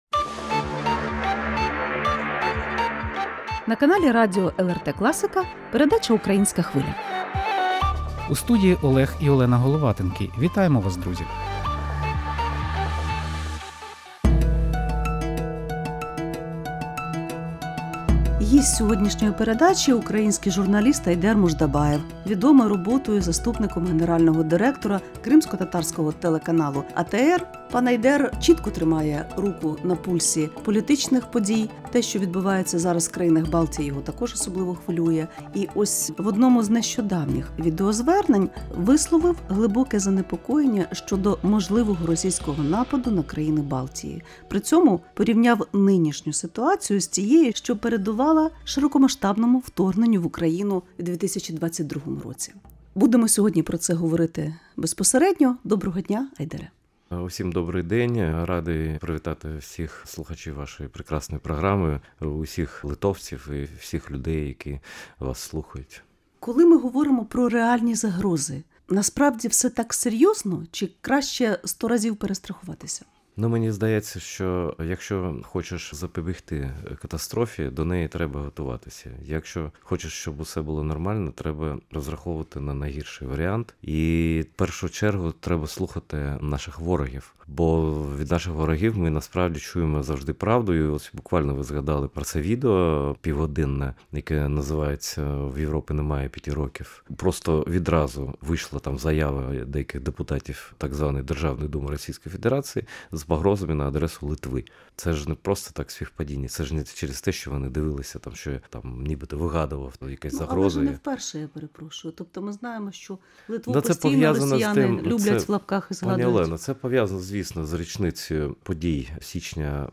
Гість студії, український журналіст Айдер Муждабаєв стверджує, що у Європи вже немає в запасі прогнозованих раніше 5 років для підготовки до війни з РФ. Росіяни зможуть перевірити НАТО на міцність найближчим часом.